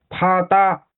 啪嗒/Pā dā/(Onomatopeya) tamborileo (de pasos que golpean).